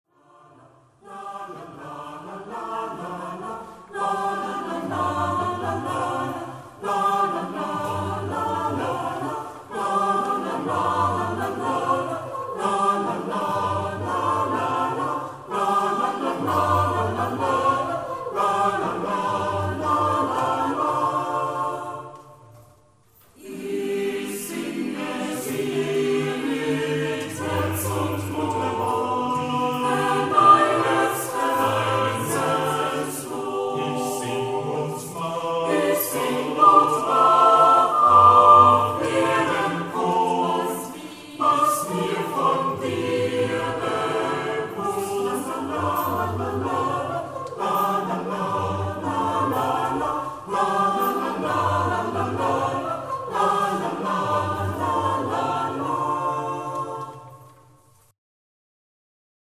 jazz choir
Dieses schwungvoll-fröhliche Loblied
Besetzung: S.S.A.T.B.